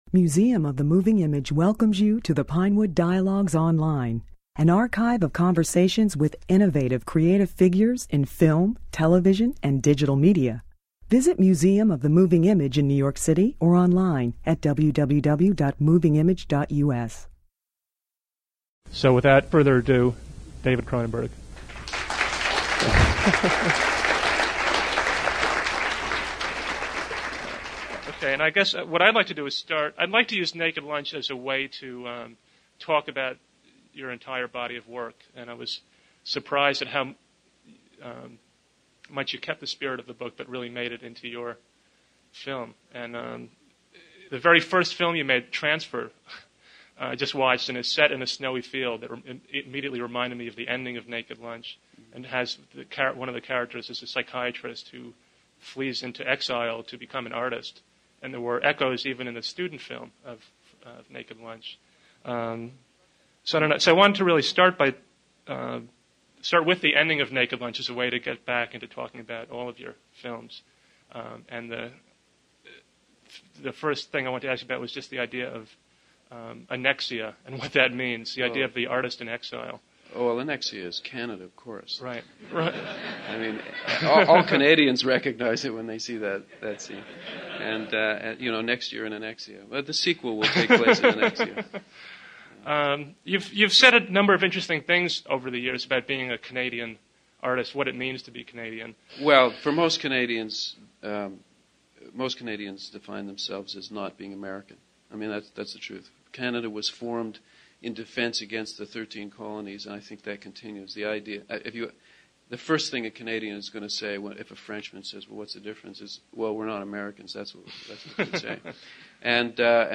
Cronenberg spoke at the Museum with a premiere screening of Naked Lunch on the opening day of a complete retrospective of his films.